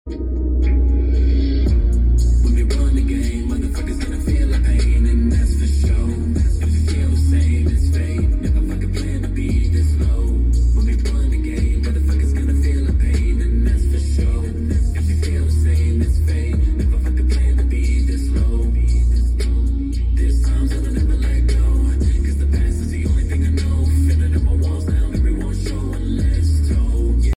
Front Subwoofer In A Car Sound Effects Free Download